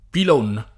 pilone
[ pil 1 ne ]